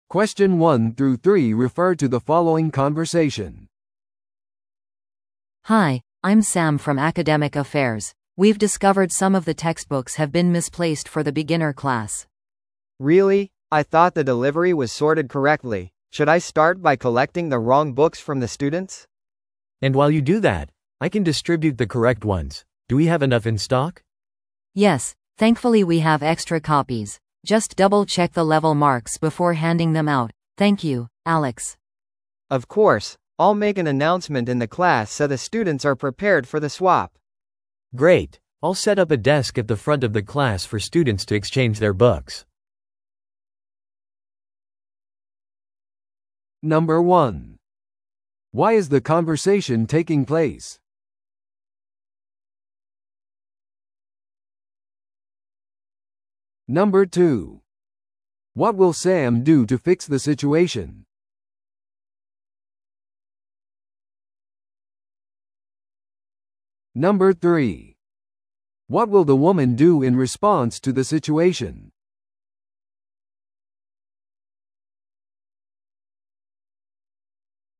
No.3. What will the woman do in response to the situation?